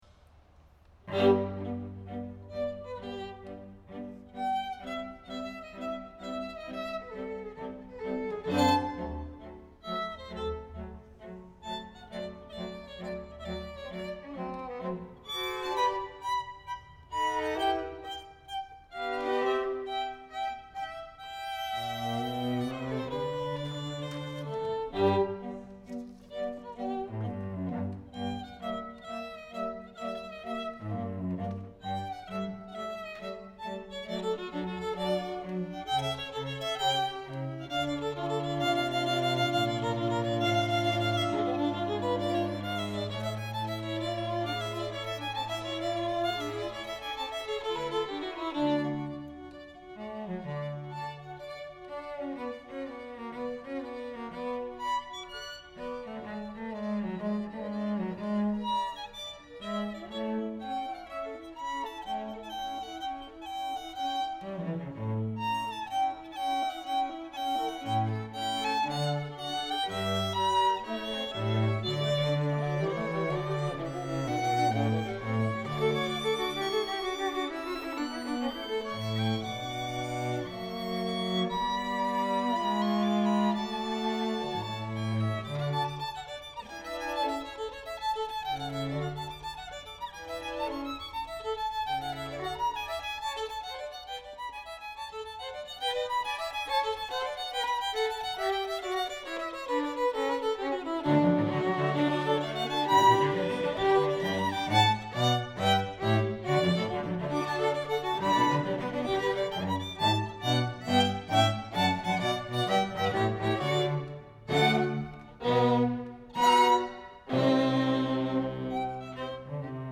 Chamber Groups
Allegro moderato